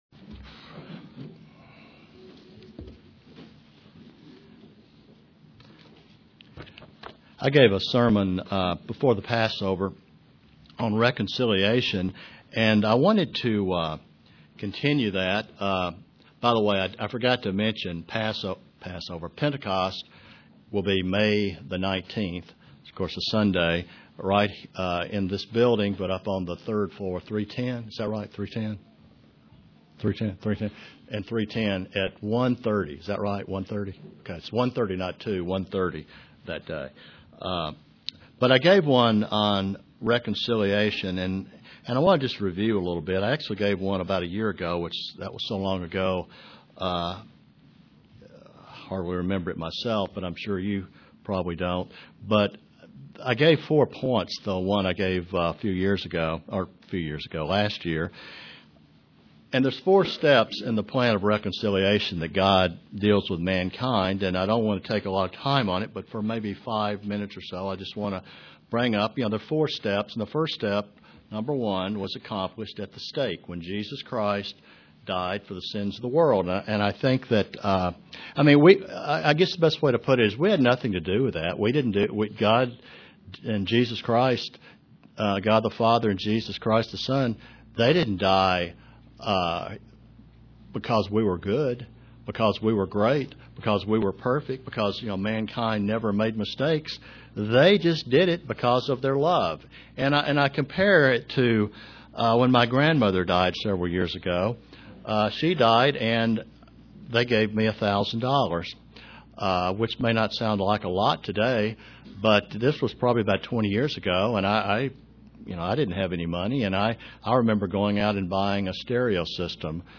Print What does a Christian do when offended UCG Sermon Studying the bible?